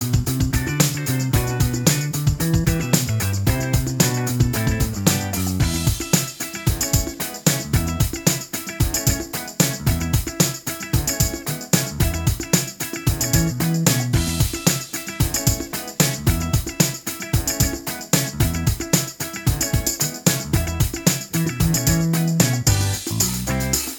Minus Main Guitar Disco 3:12 Buy £1.50